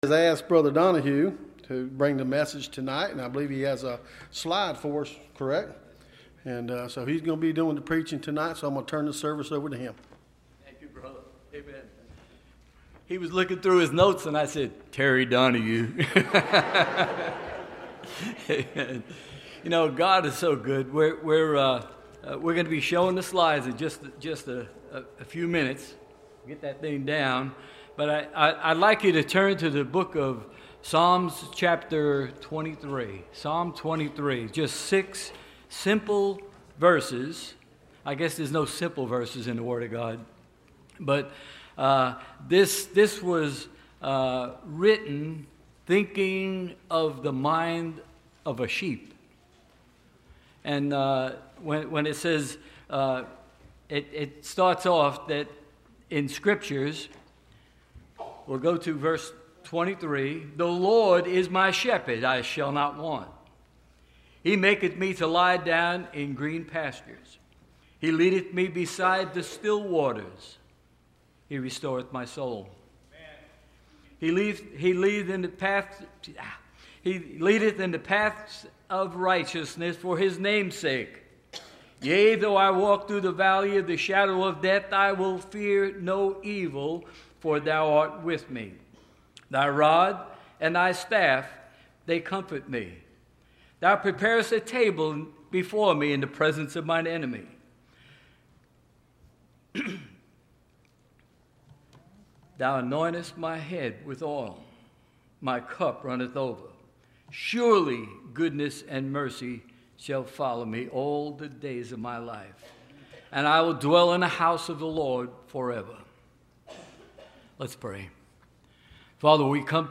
Missionary Presentation – Landmark Baptist Church
Service Type: Wednesday